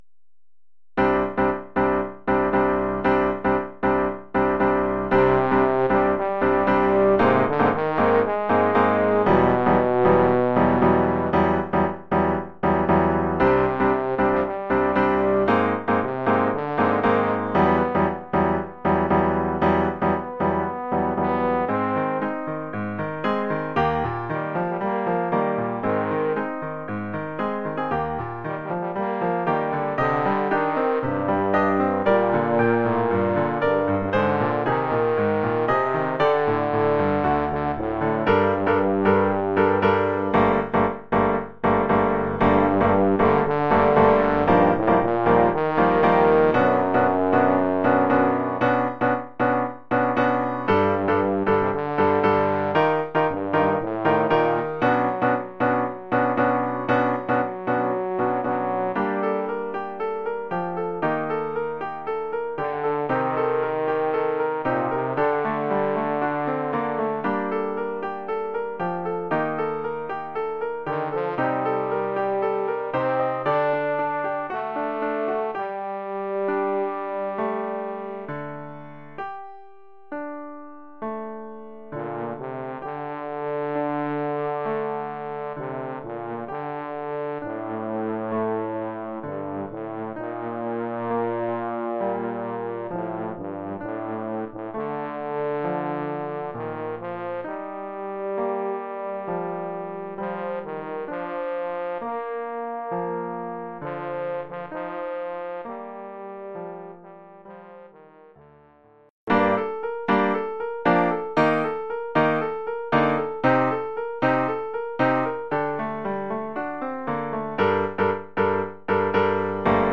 Formule instrumentale : Trombone et piano
Oeuvre pour trombone et piano.